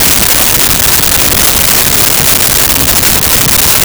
Train Loop
Train Loop.wav